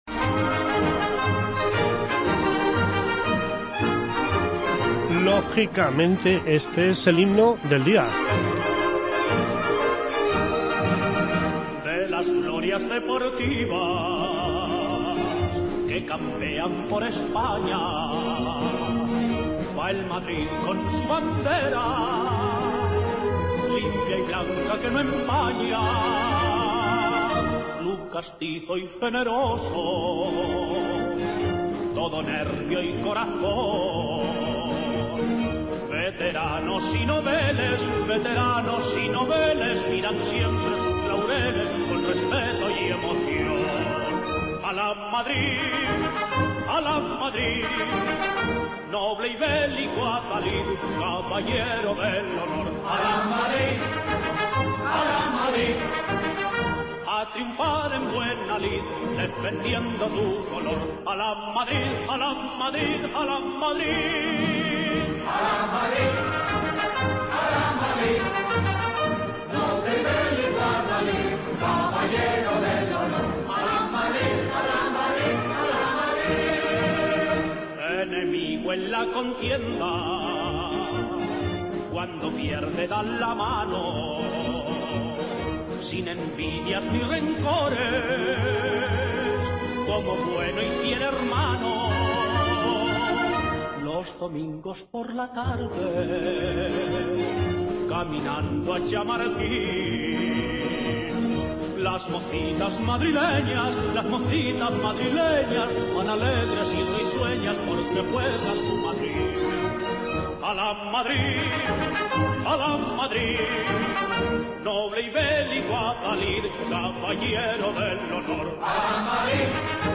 El programa de este jueves ha estado centrado en la conversación con la consejera de Fomento, Elena de la Cruz.